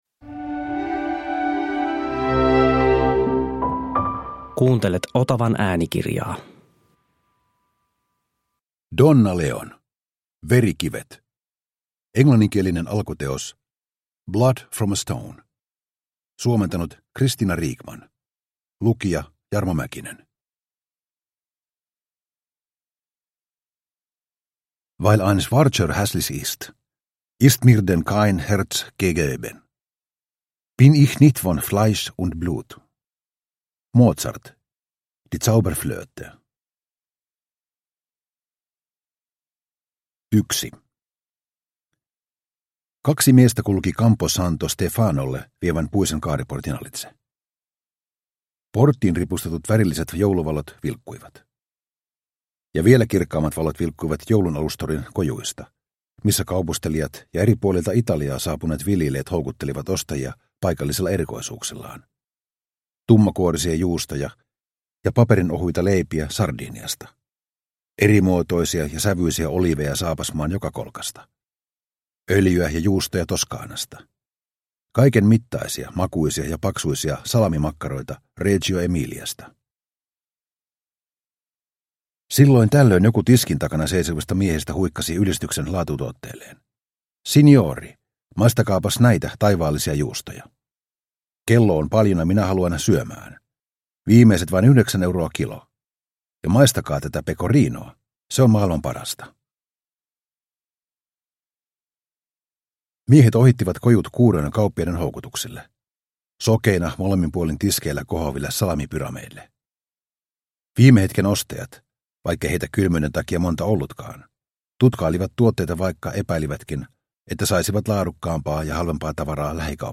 Verikivet – Ljudbok – Laddas ner